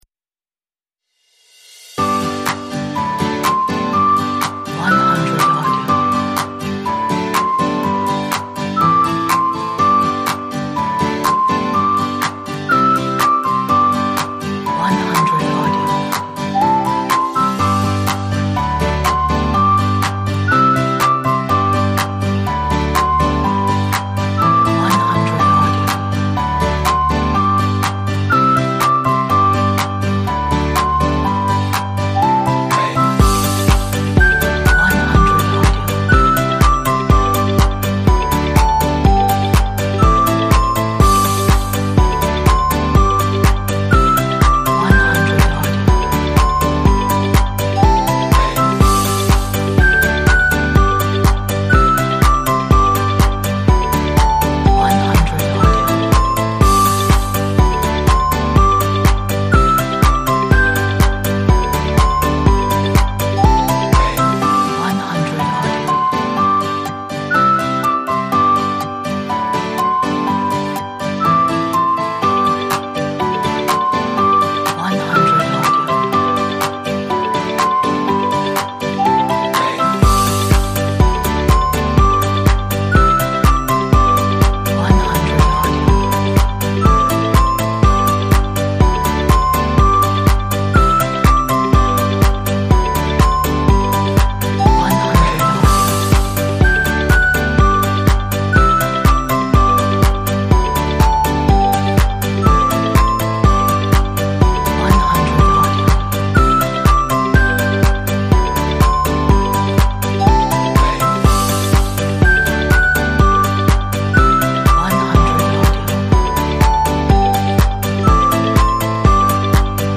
A bright inspiring optimistic New Year track !!!!